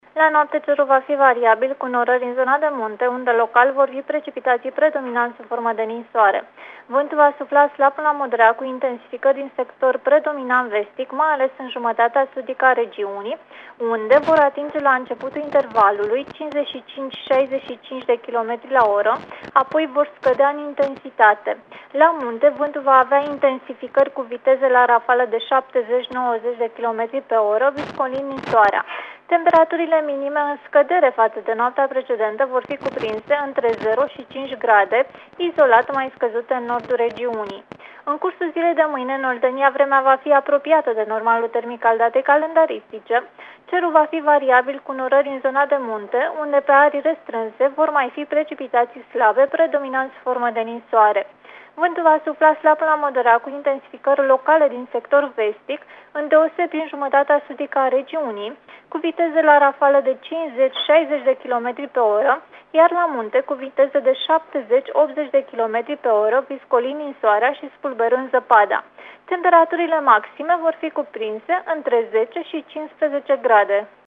Prognoza meteo 24/25 octombrie (audio)